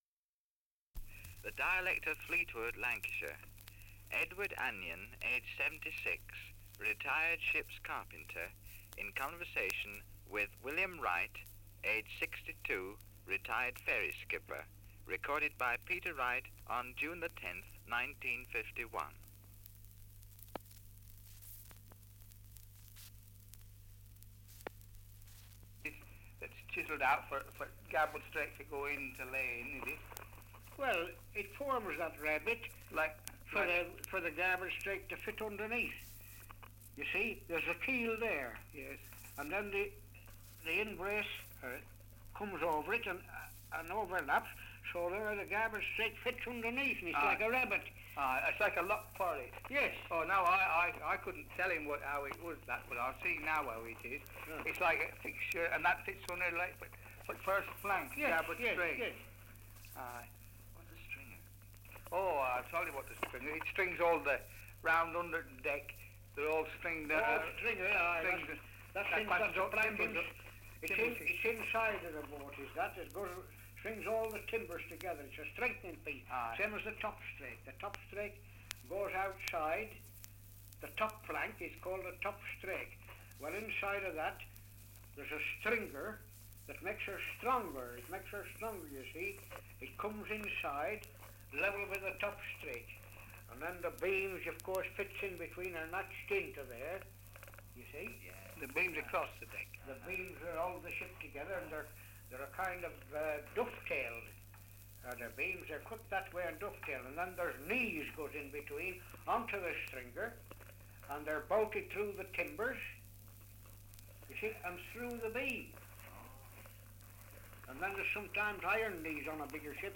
Survey of English Dialects recording in Fleetwood, Lancashire
78 r.p.m., cellulose nitrate on aluminium